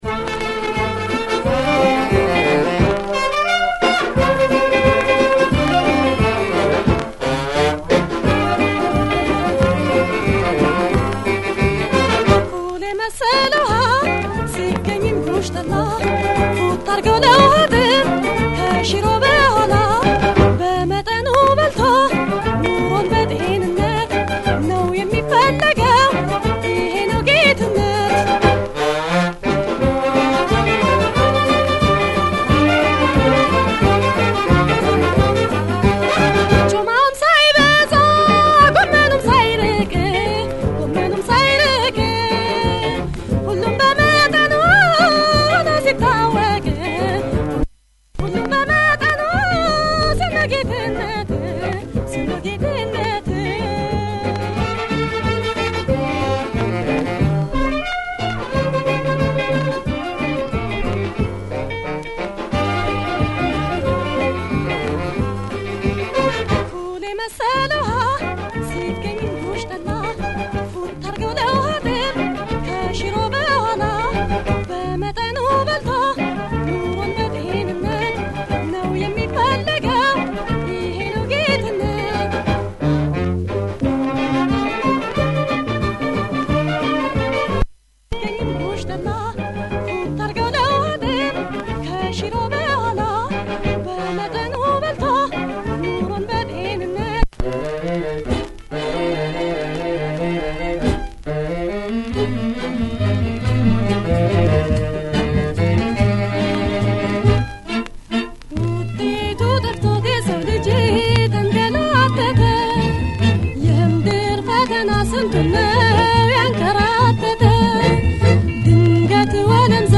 Good Ethio shaker